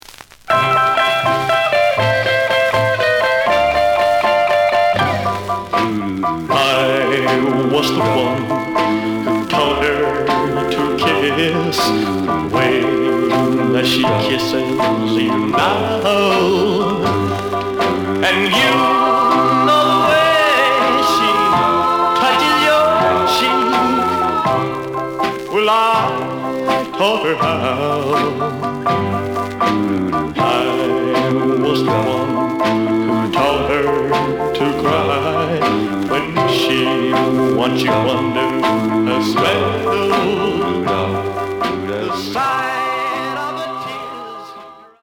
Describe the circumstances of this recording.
The audio sample is recorded from the actual item. Some noise on B side.)